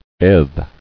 [edh]